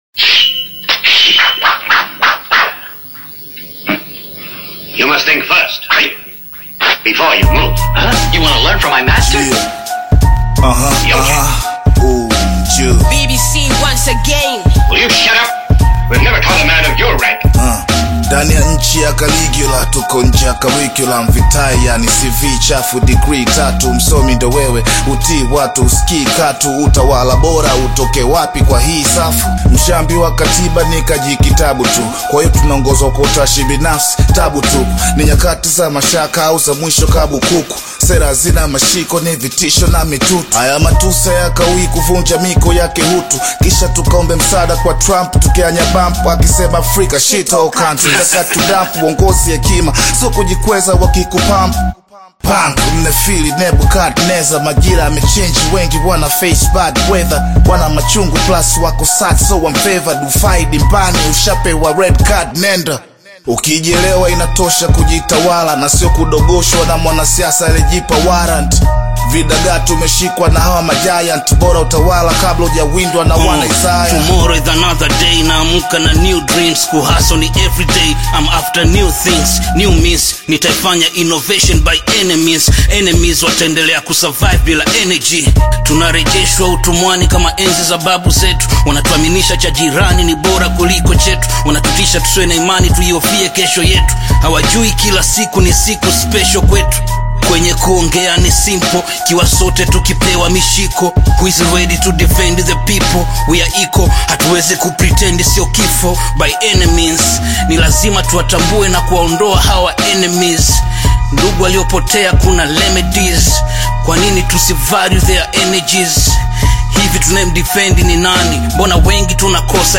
is a high-energy Tanzanian hip hop collaboration